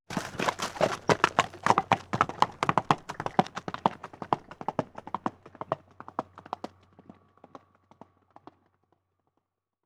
Caballo saliendo al galope sobre tierra 2